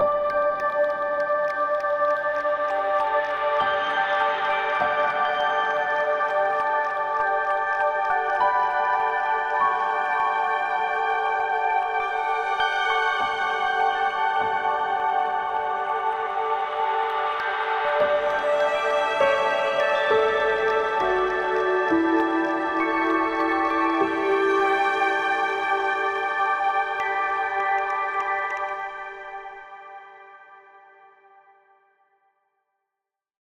Added Ambient music pack. 2024-04-14 17:36:33 -04:00 9.2 MiB Raw Permalink History
Ambient Wonderlust cut 30.wav